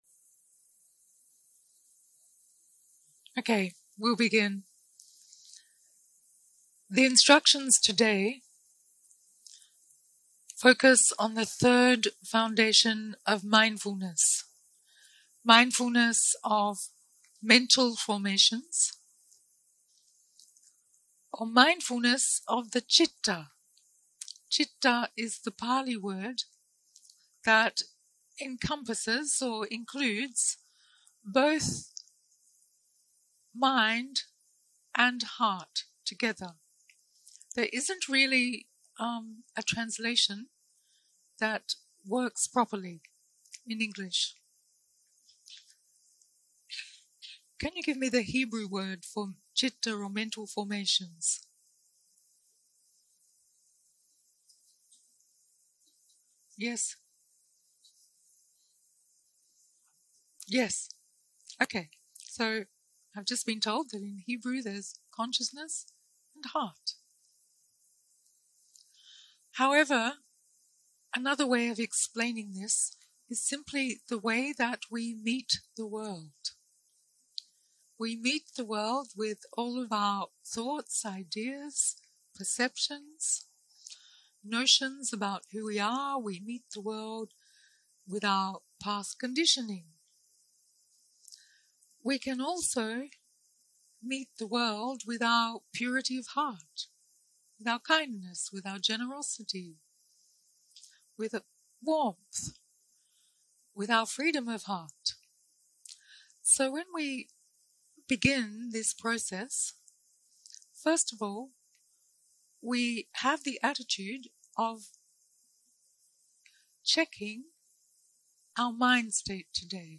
יום 3 - הקלטה 5 - בוקר - הנחיות למדיטציה - אנאפאנהסטי סוטה קטגוריה ג (מצבי תודעה) 0:00 0:00 סוג ההקלטה: Dharma type: Guided meditation שפת ההקלטה: Dharma talk language: English